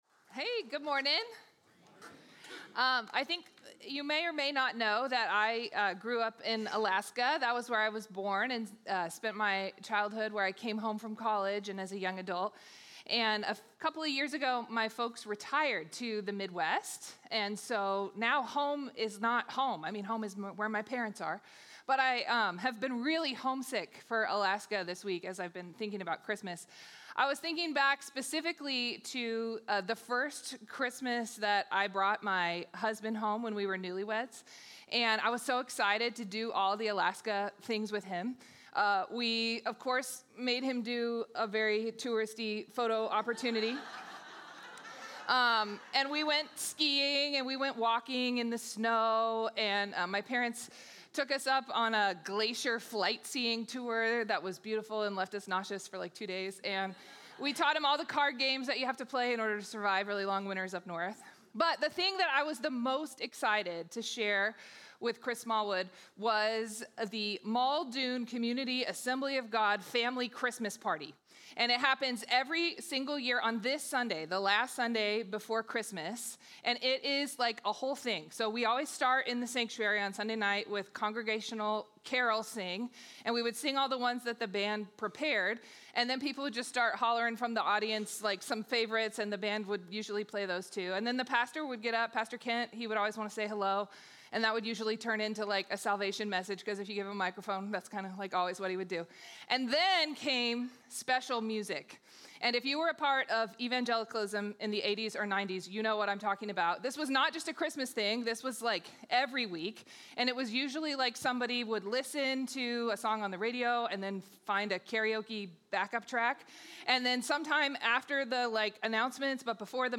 The River Church Community Sermons